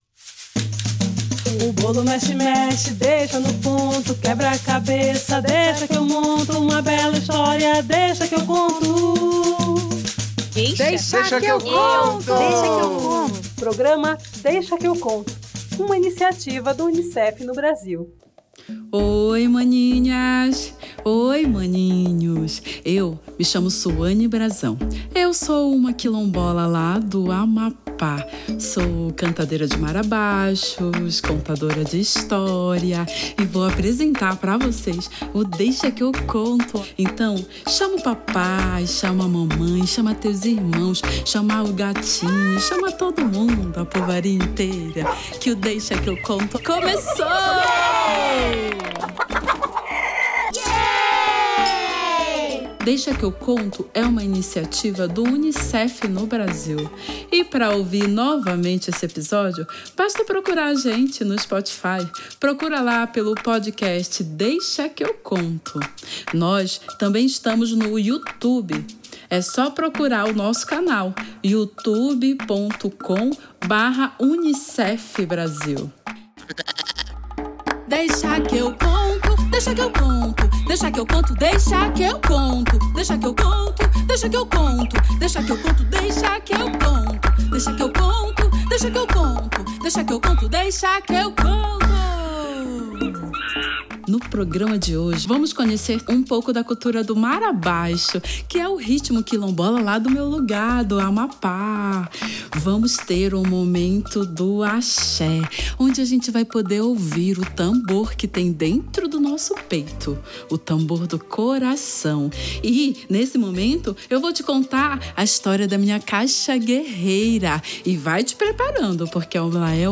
Seguida do momento pavulagem, onde as crianças podem interagir, com suas características.